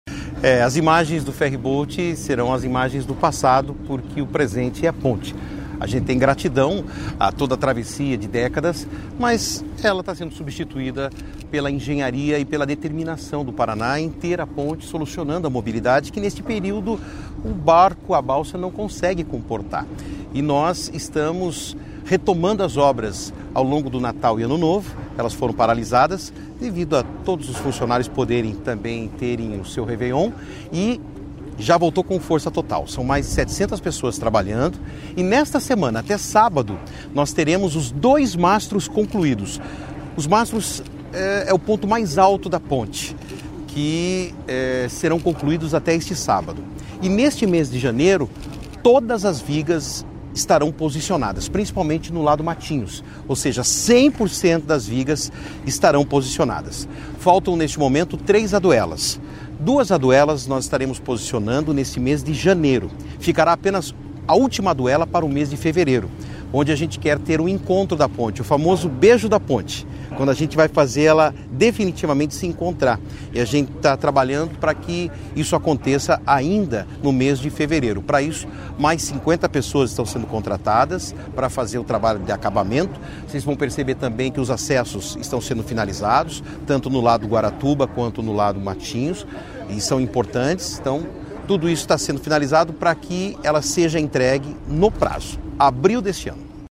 Sonora do secretário Estadual da Infraestrutura e Logística, Sandro Alex, sobre o andamento da obra da Ponte de Guaratuba | Governo do Estado do Paraná